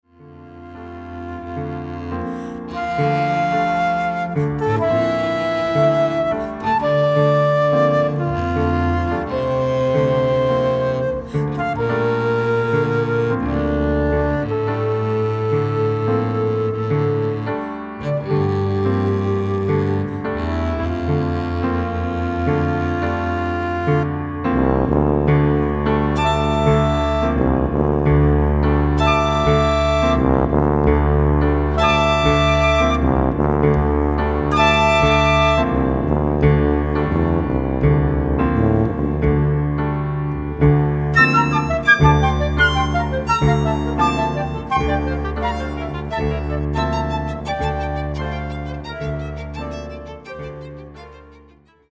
Nahráno v zimě 2003/2004 ve studiu PIVOX